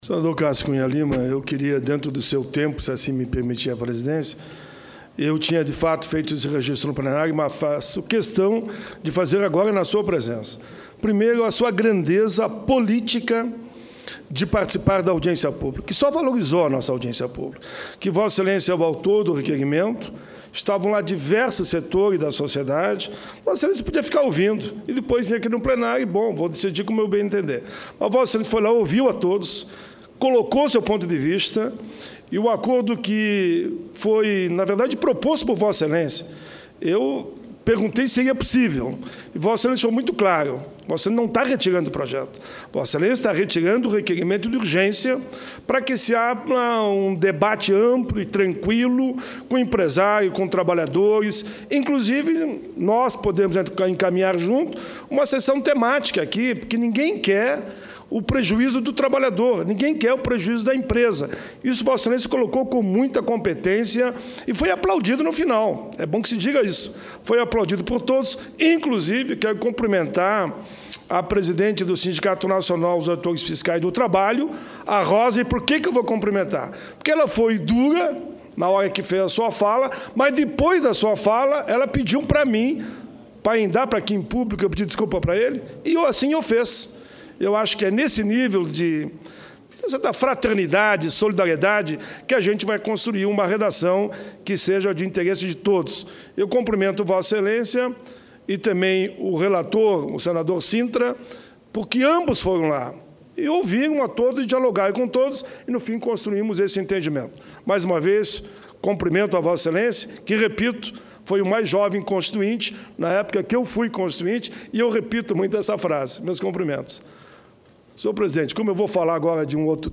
Discursos